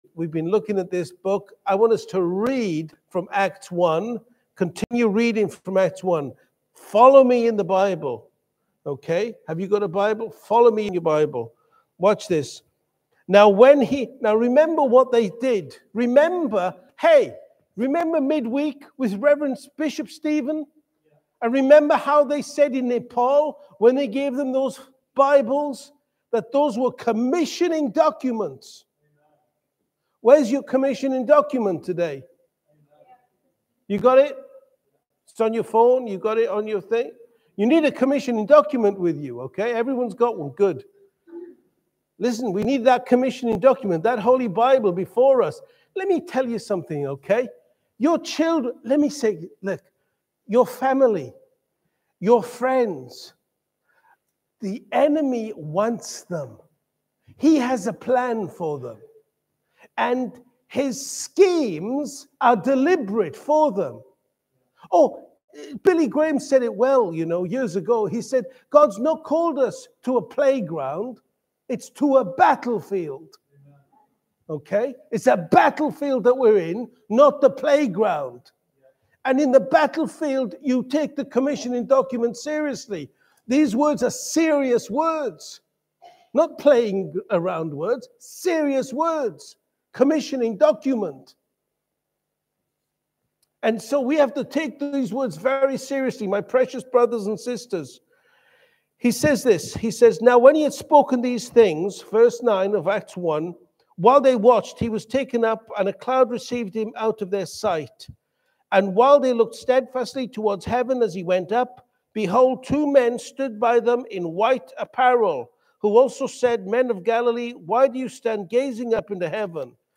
Audio and video teachings from Living Faith Church